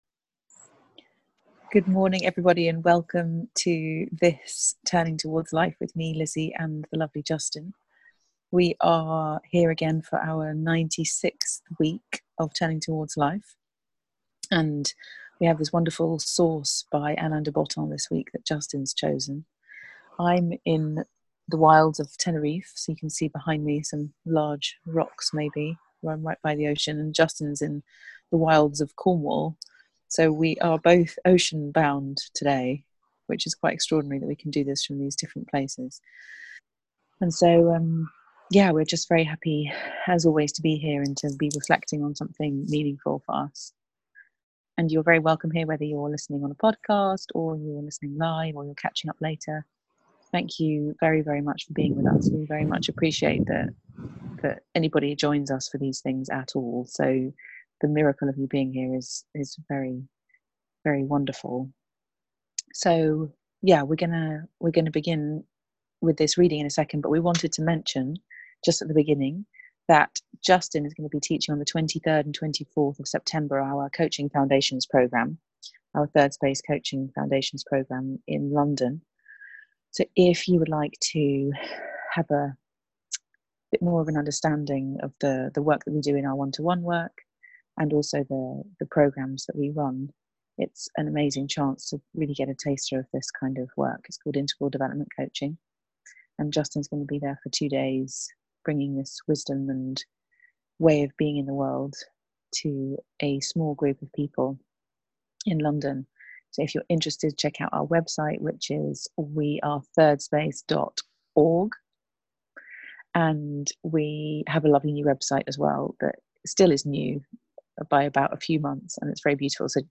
a weekly live 30 minute conversation